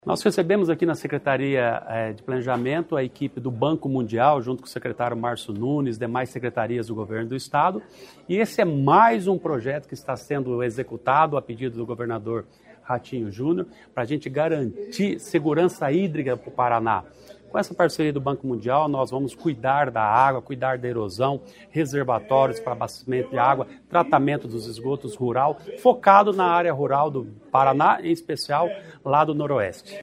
Sonora do secretário Estadual do Planejamento, Ulisses Maia, sobre as ações do Programa de Segurança Hídrica